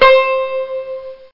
Banjo Hi Sound Effect
banjo-hi.mp3